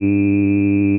I det här fallet koncentreras energin i tre ”spetsar”, vid 300 Hz, 2400 Hz och 2600 Hz. Detta ger upphov till vokalen [i] (”iih”).